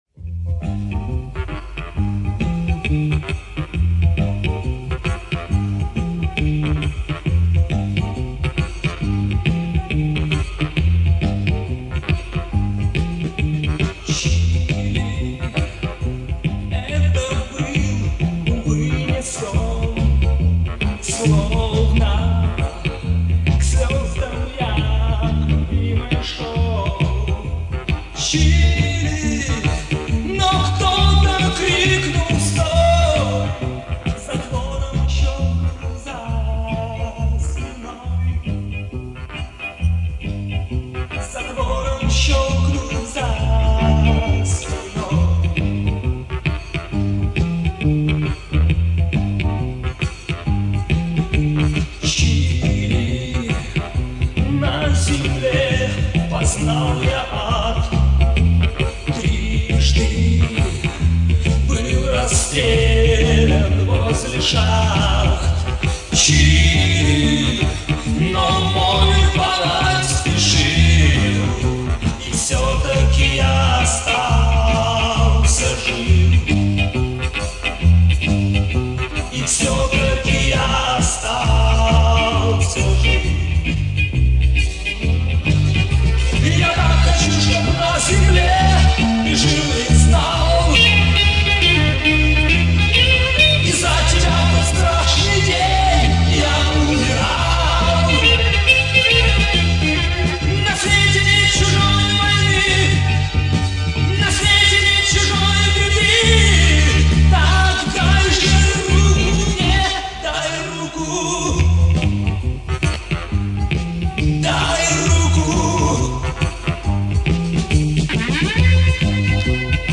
клавишник